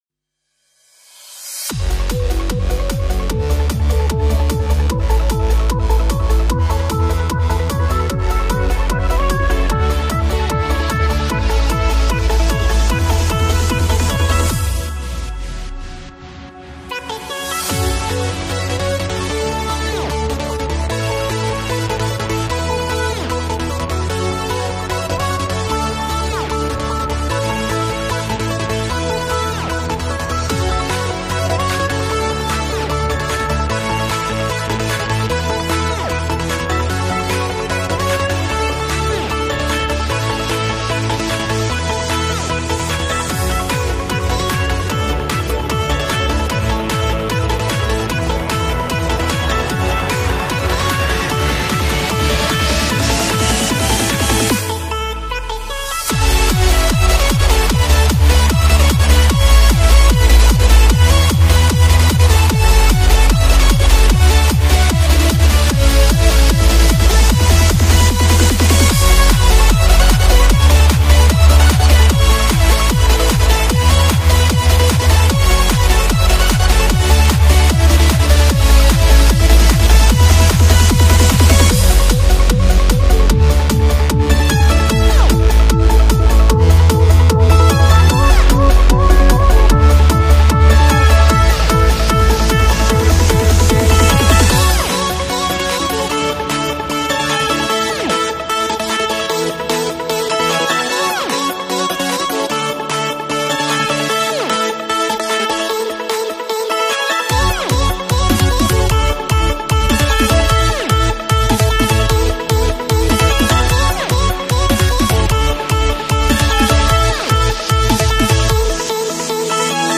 Hardstyle, Happy, Hopeful, Epic, Euphoric, Energetic